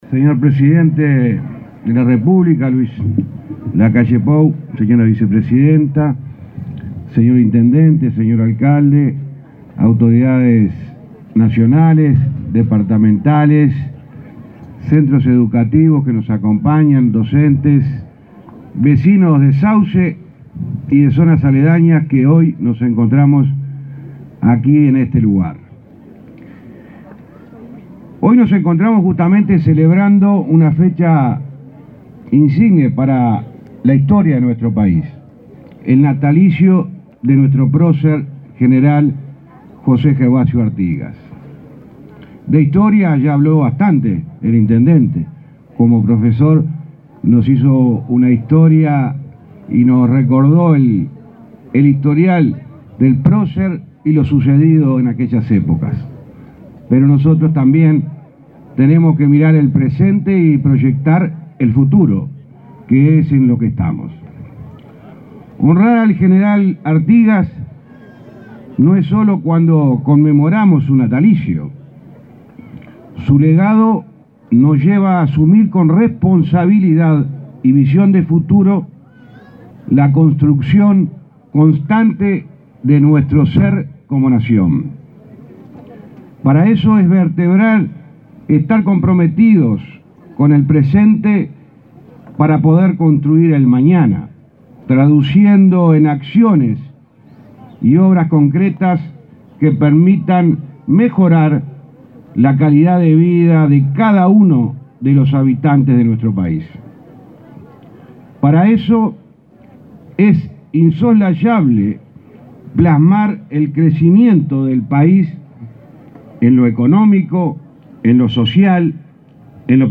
El ministro de Transporte, José Luis Falero, fue el orador central del acto de conmemoración del 258.° aniversario del natalicio del gral. Gervasio